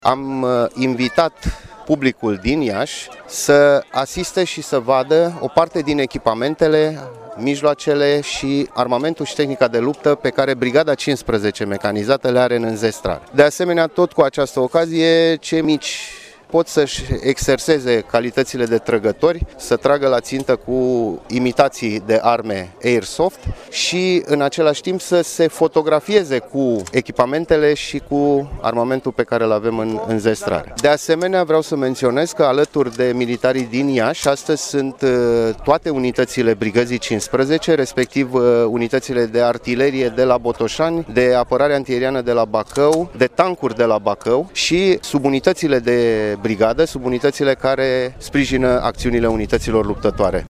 La Iaşi, Ziua Forțelor Terestre din România esa fost marcată  printr-o expoziție de tehnică militară.